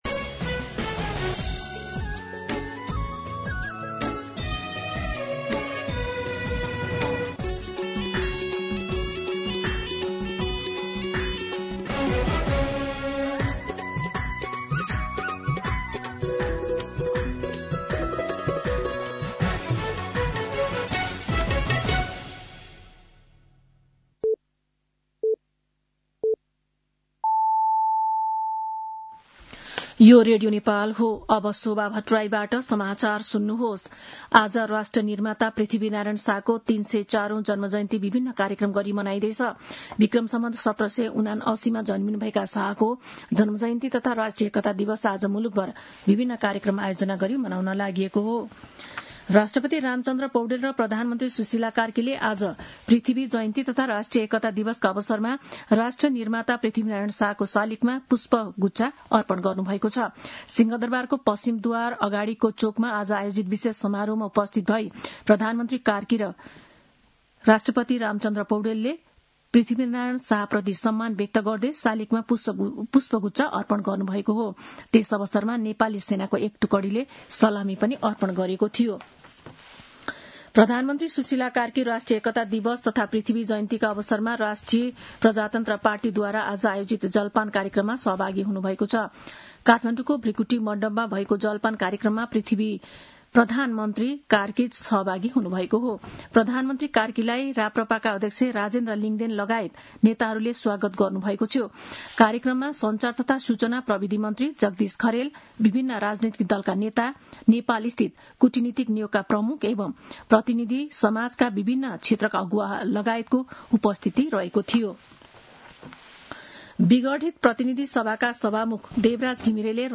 दिउँसो ४ बजेको नेपाली समाचार : २७ पुष , २०८२
4pm-News.mp3